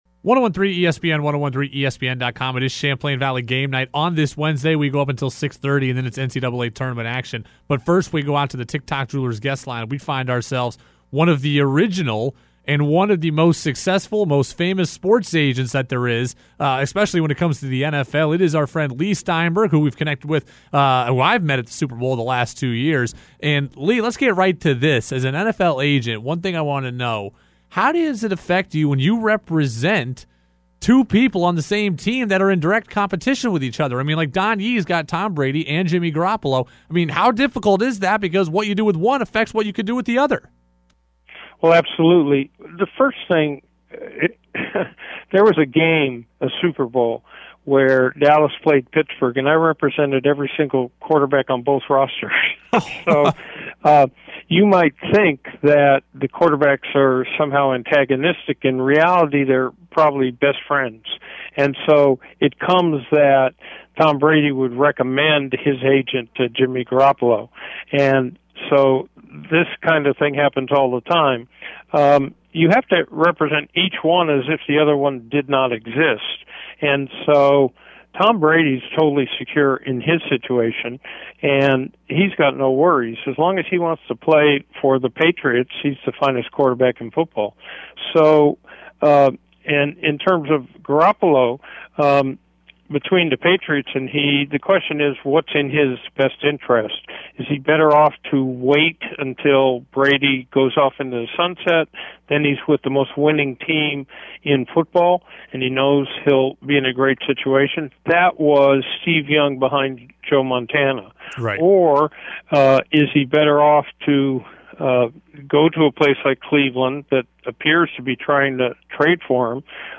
He joined us today on ‘Champlain Valley Game Night’ to talk about what how free agent negotiations begin and come to fruition.